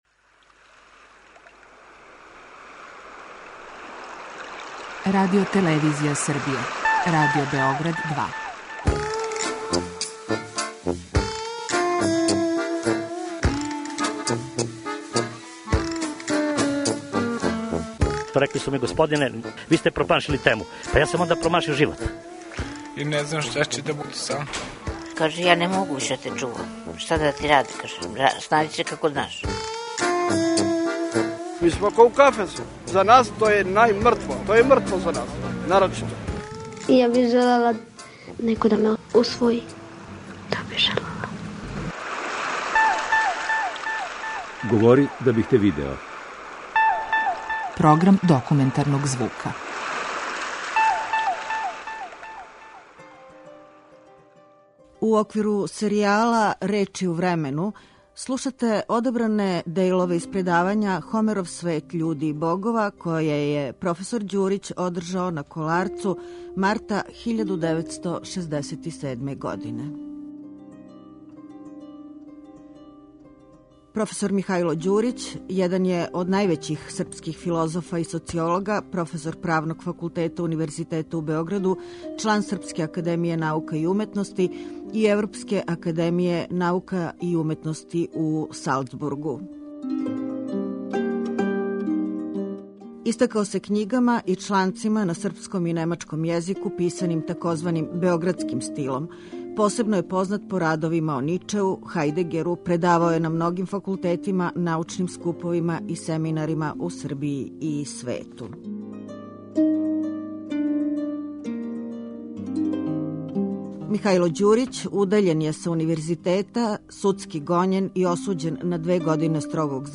РЕЧИ У ВРЕМЕНУ - звучни записи предавања на Коларцу